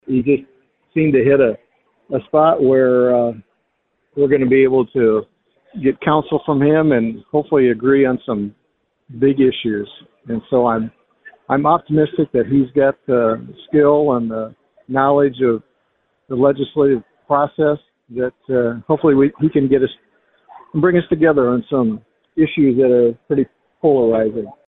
District 24 Representative Mike Weisgram of Fort Pierre said Rhoden may be “the right man, at the right time.”